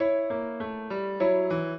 piano
minuet10-11.wav